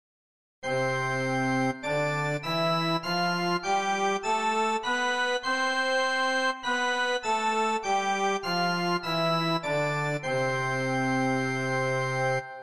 020：チャーチオルガン（Church organ）
ＧＭ音源プログラムチェンジの２０番は、チャーチオルガン（Church organ）の音色です。
ただ、ＧＭ音源では、ひとつのプログラムでひとつの音色のみと決められていますので、発音できる数多くの音色の中から一般的なパイプオルガンの音色が収録されていることが多いです。